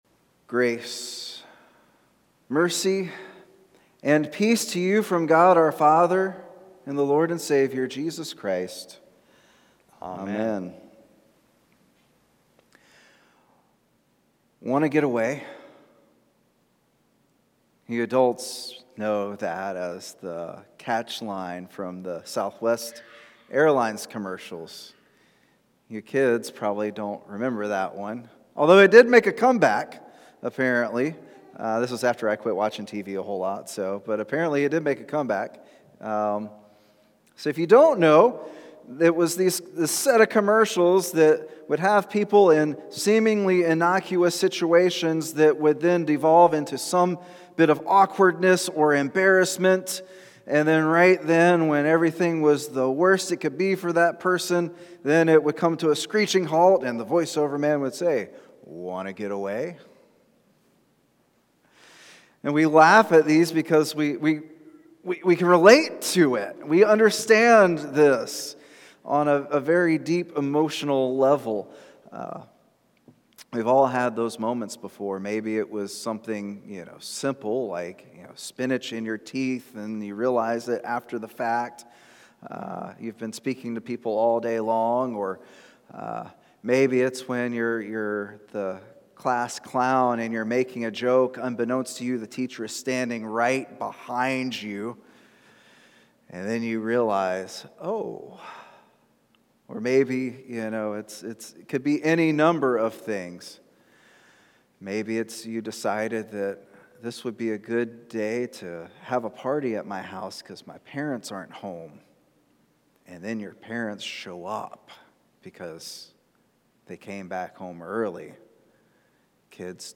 Places of the Passion Passage: Luke 22:54-62 Service Type: Special « What Are You Still Bumping Into?